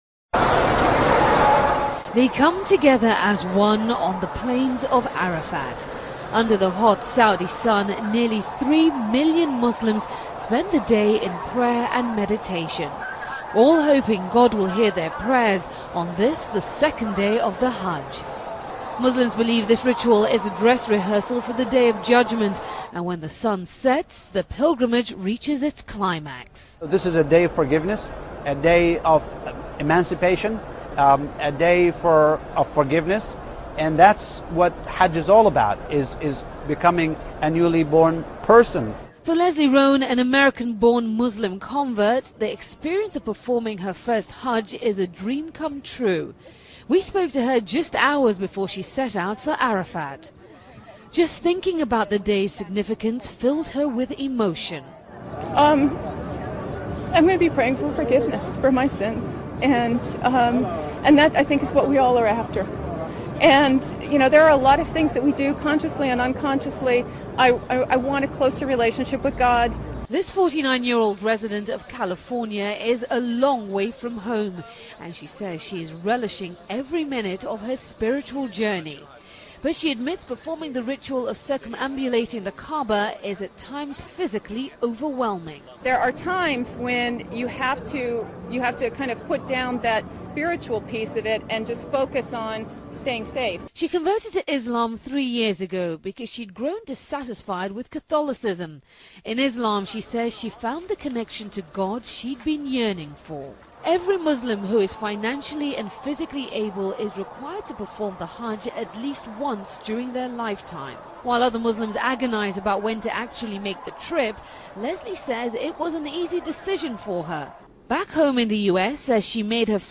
American Woman on Hajj (CNN)